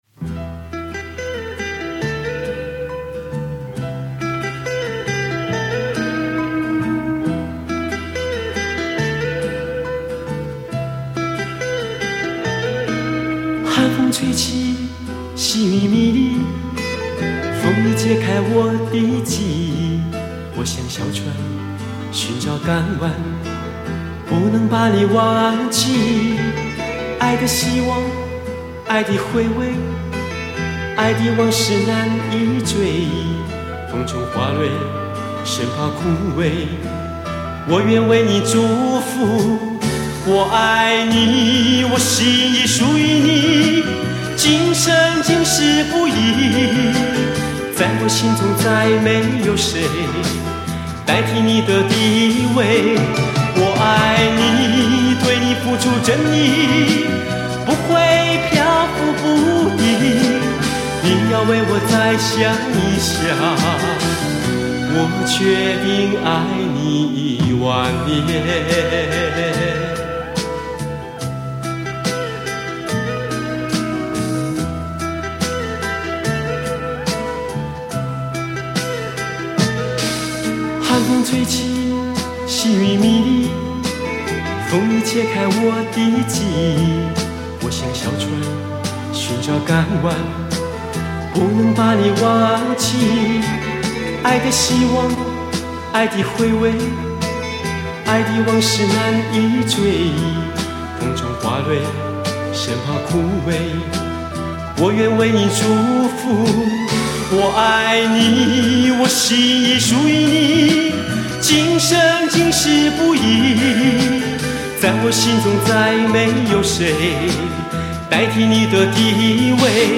原始母带24Bit音质处理 优质典藏